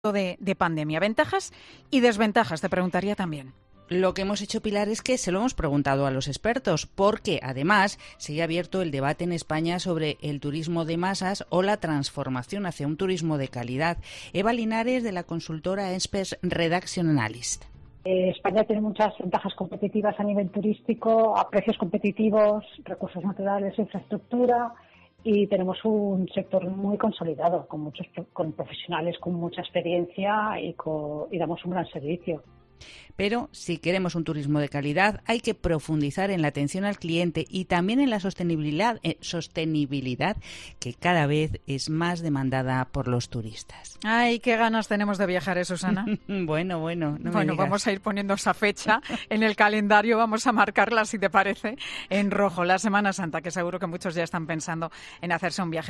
Entrevistas en los medios a Expense Reduction Analysts